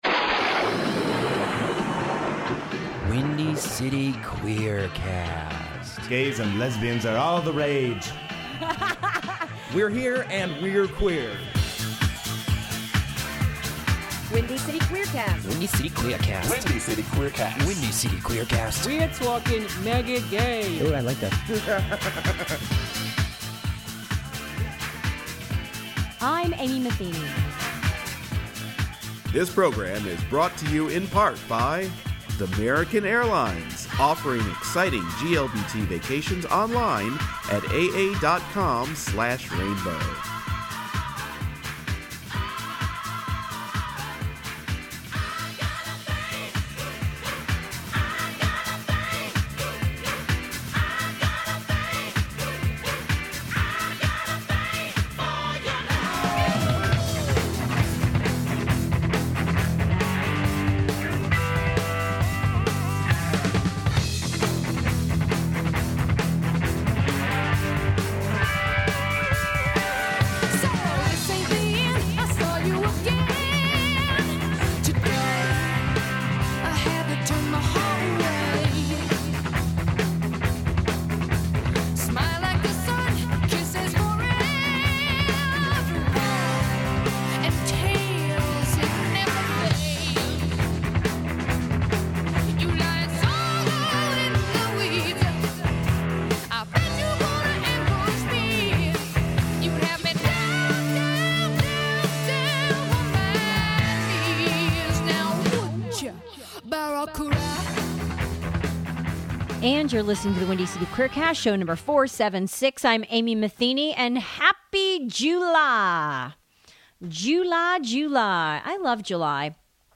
Guest: Heart lead singer, Ann Wilson...